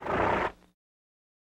Звуки фырканья лошади
На этой странице собраны разнообразные звуки фырканья лошадей — от игривого до раздраженного.